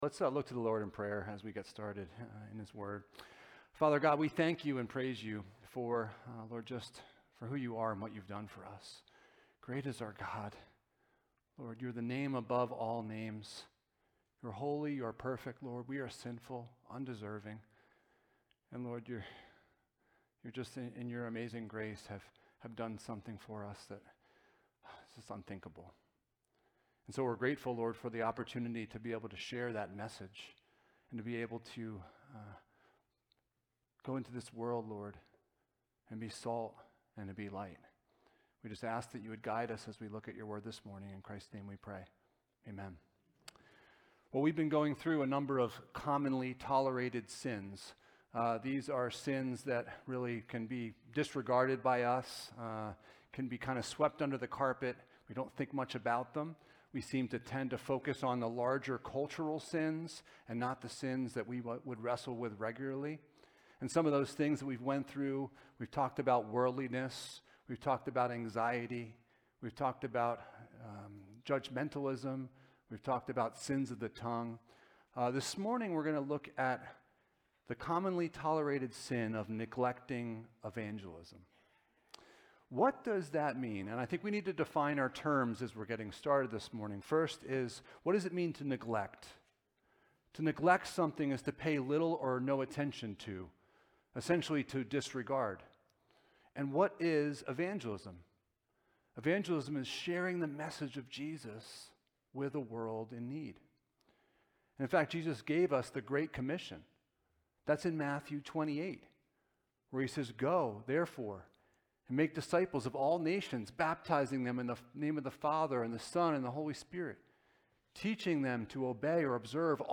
Passage: Matthew 5: 13-16 Service Type: Sunday Morning